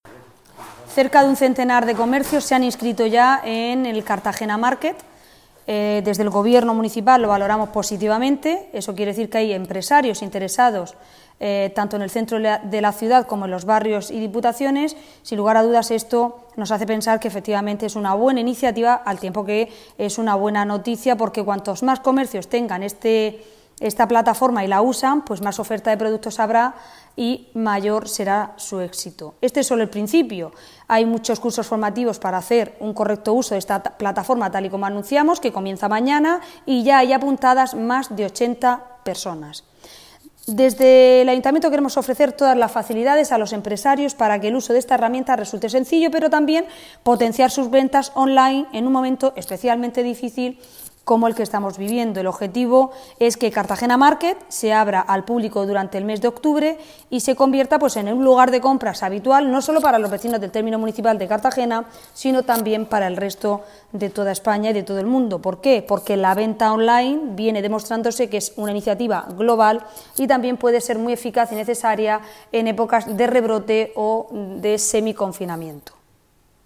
Enlace a Declaraciones alcaldesa sobre Cartagena Market